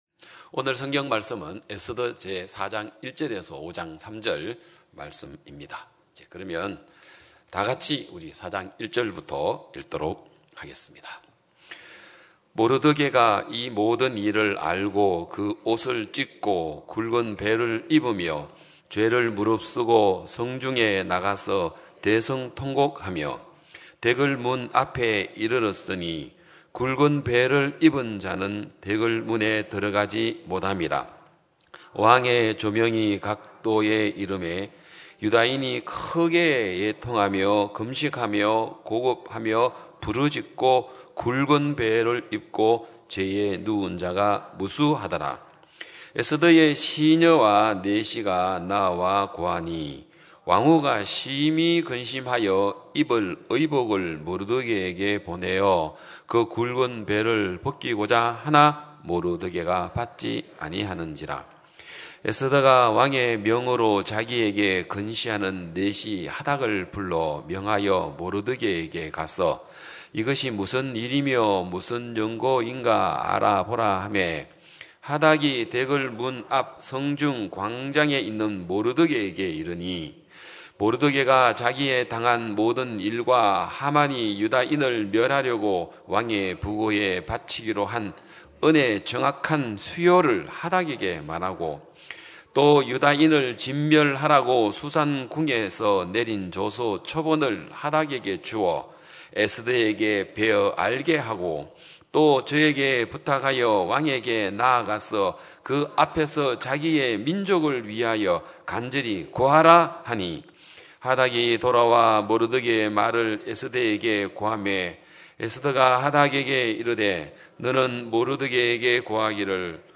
2022년 5월 22일 기쁜소식양천교회 주일오전예배
성도들이 모두 교회에 모여 말씀을 듣는 주일 예배의 설교는, 한 주간 우리 마음을 채웠던 생각을 내려두고 하나님의 말씀으로 가득 채우는 시간입니다.